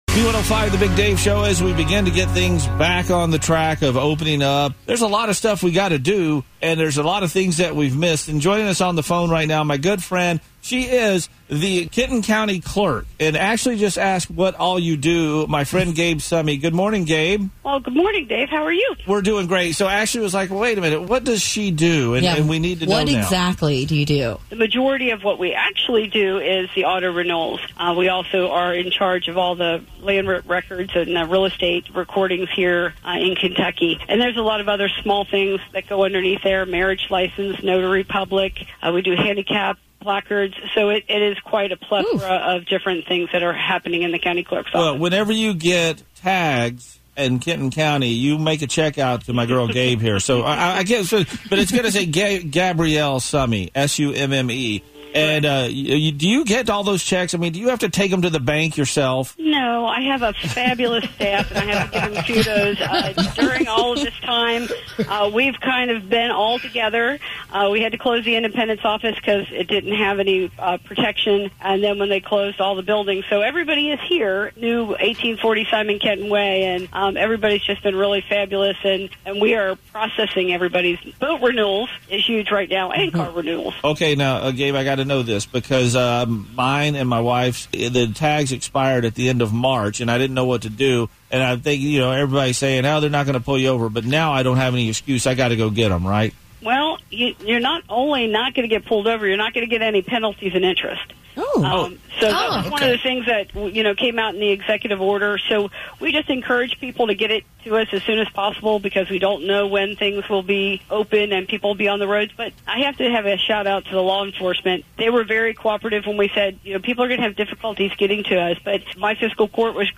Keeper-20-Gabe-Summe-Interview.mp3